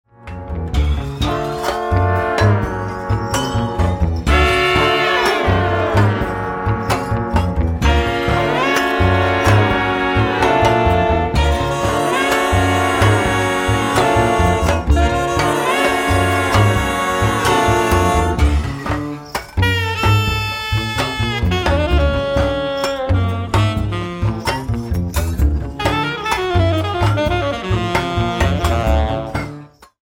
trumpet
Music recorded 2011 at Loft, Cologne